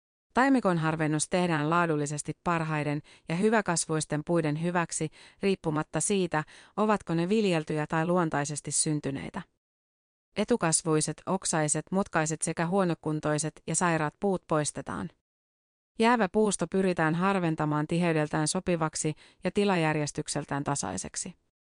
Äänikirjan on lukenut a.i.materin koneääni Ilona.